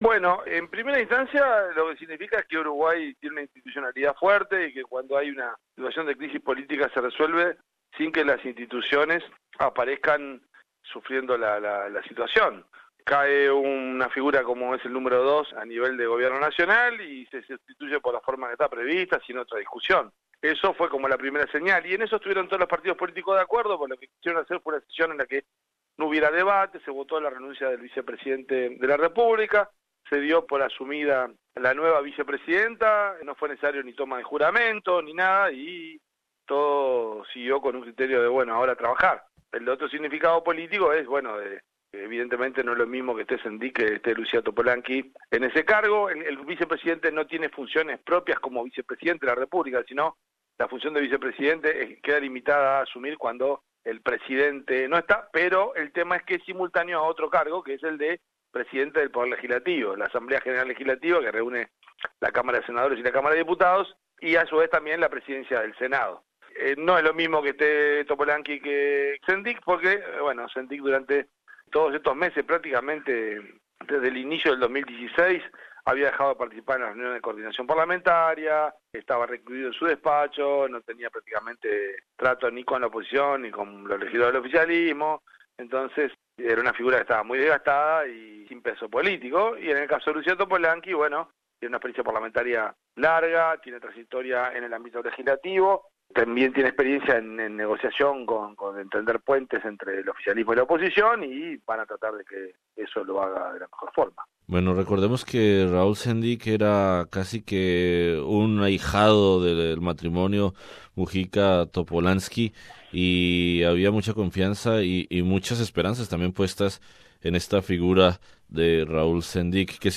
El periodista y catedrático en Montevideo